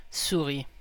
Ääntäminen
France (Paris): IPA: /su.ʁi/